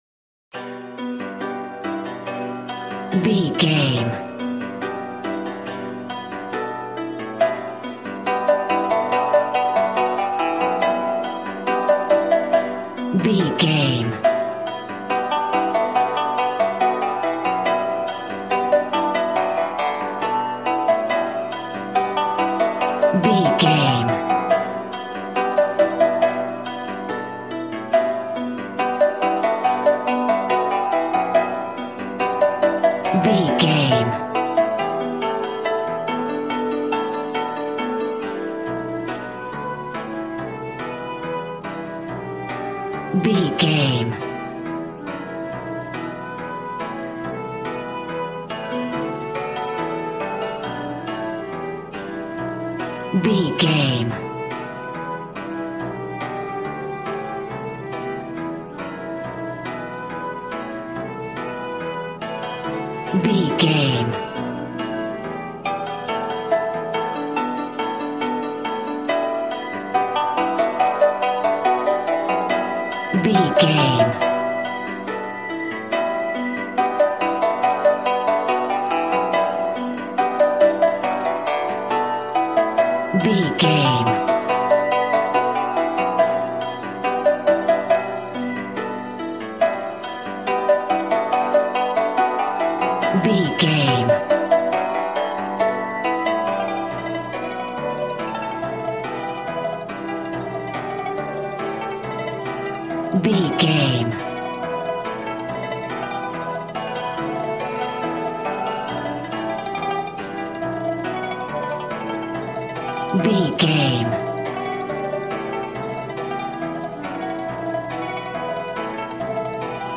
Aeolian/Minor
smooth
conga
drums